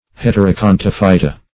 heterokontophyta - definition of heterokontophyta - synonyms, pronunciation, spelling from Free Dictionary